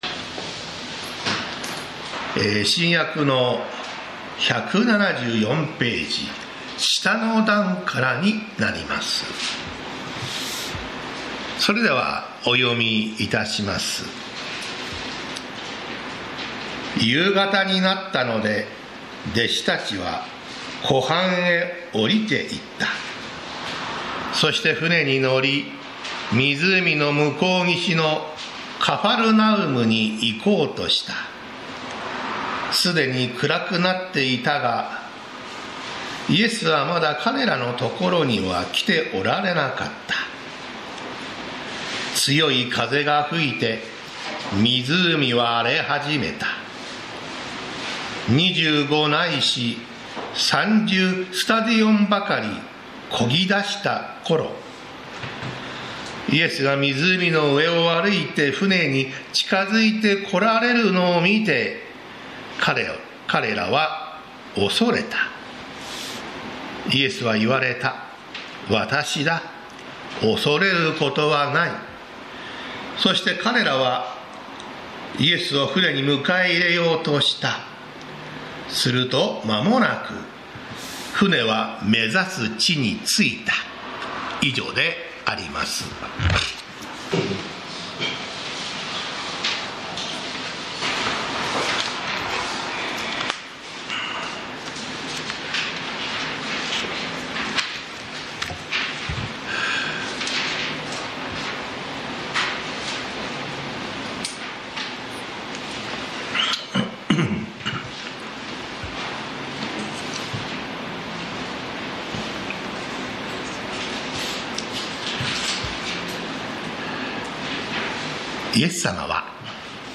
栃木県鹿沼市のプロテスタント教会。